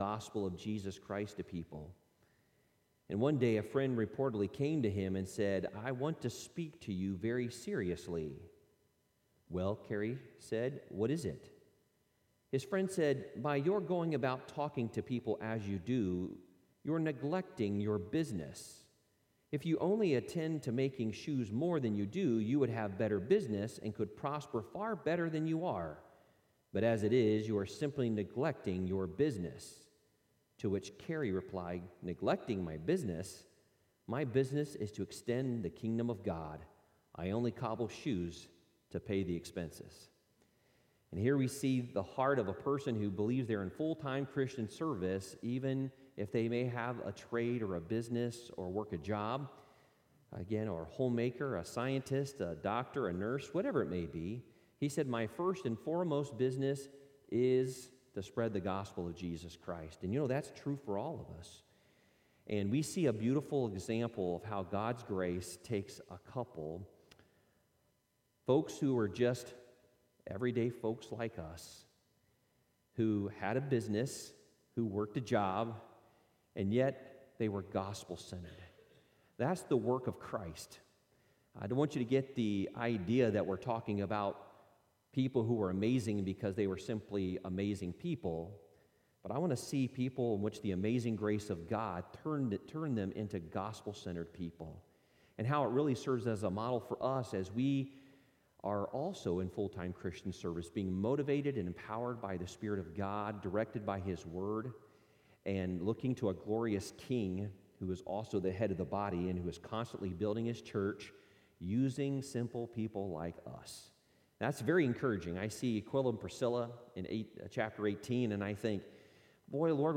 Worship Service: 10/10/2021